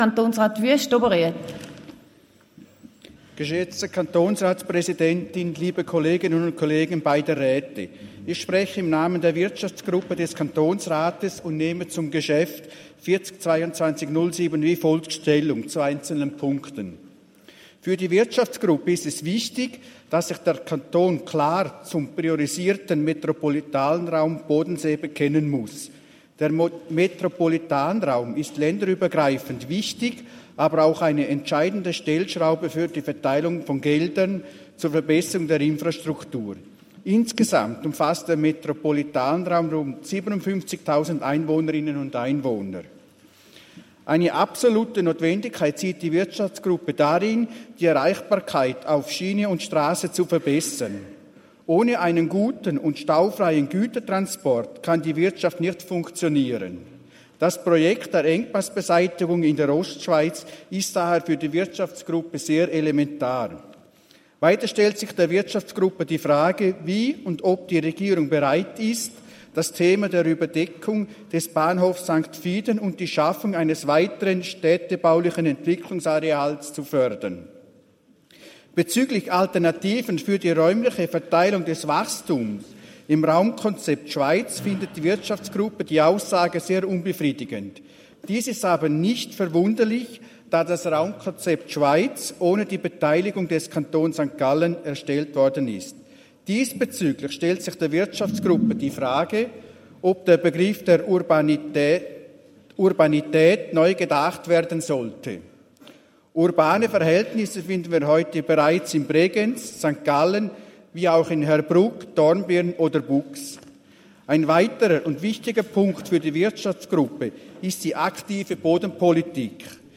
Wüst-Oberriet (im Namen der Wirtschaftsgruppe): Auf den Bericht ist einzutreten.
Session des Kantonsrates vom 18. bis 20. September 2023, Herbstsession